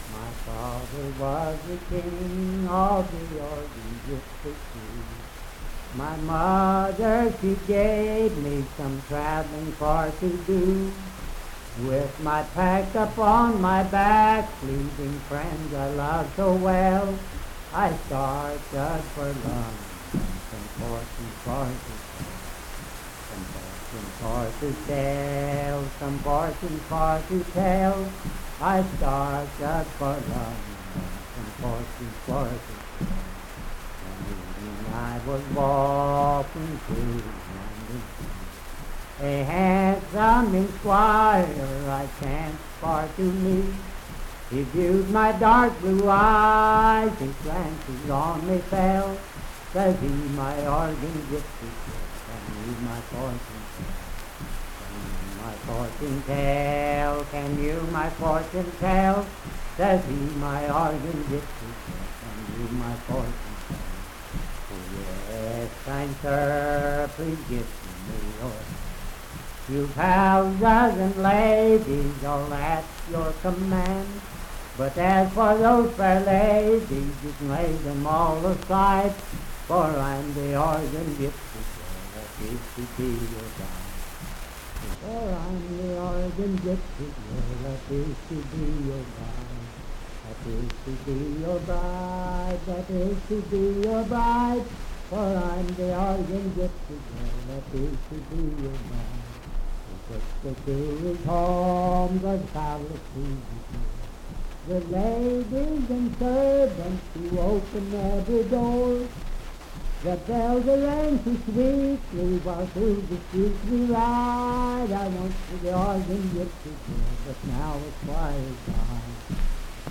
Unaccompanied vocal music and folktales
Verse-refrain 4(6w/R).
Voice (sung)
Parkersburg (W. Va.), Wood County (W. Va.)